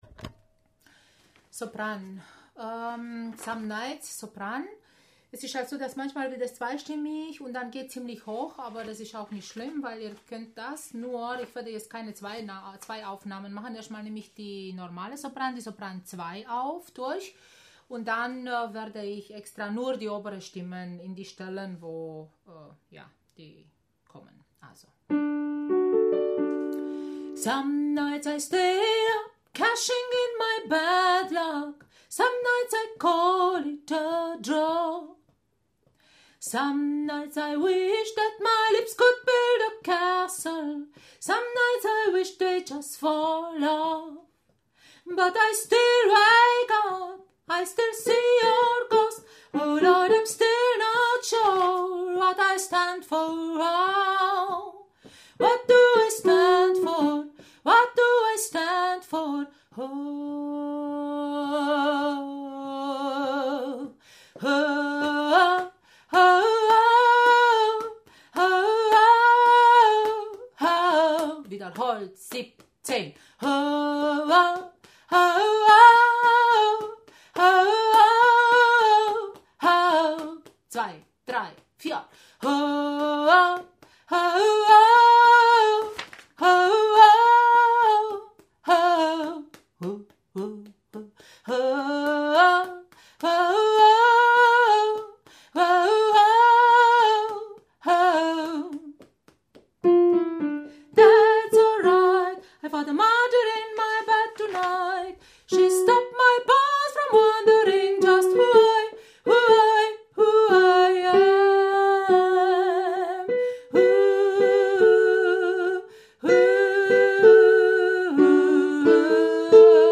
Some Nights – Sopran
Some-Nights-Sopran.mp3